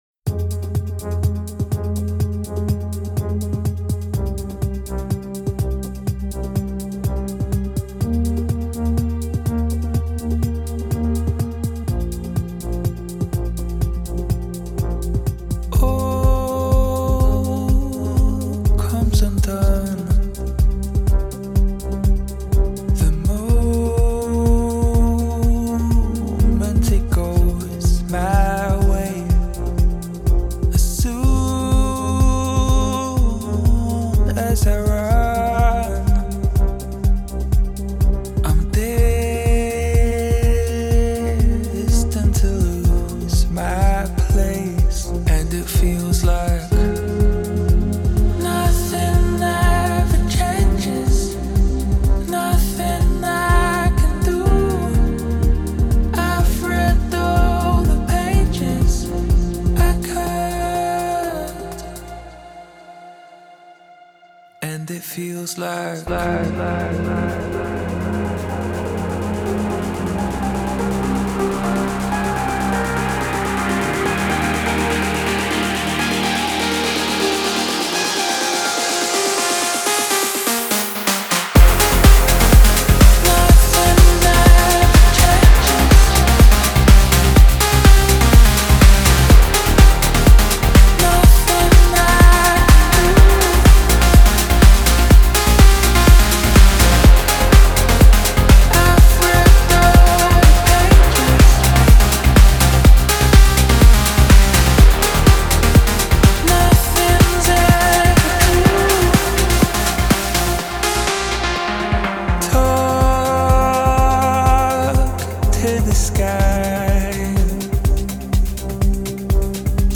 • Жанр: House, Techno